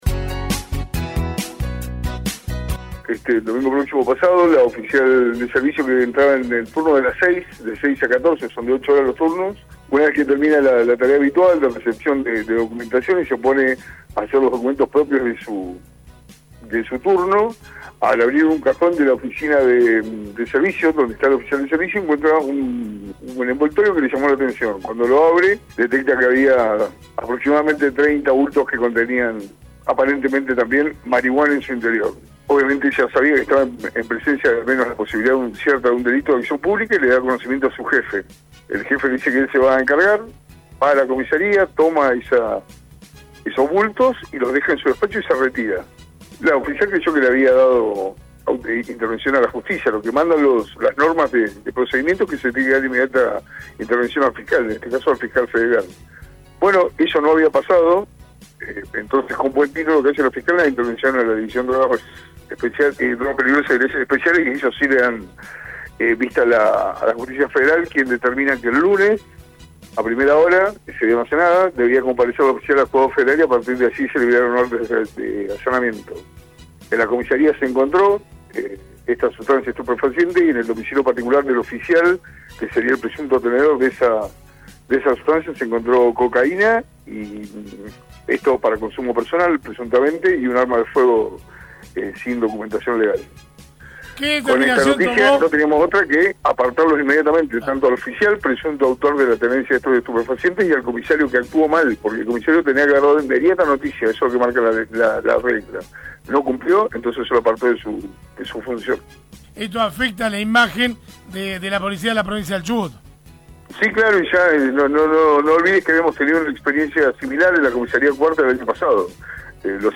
Así lo relató el Ministro de Seguridad de la provincia, Héctor Iturrioz, a los micrófonos de RADIOVISIÓN: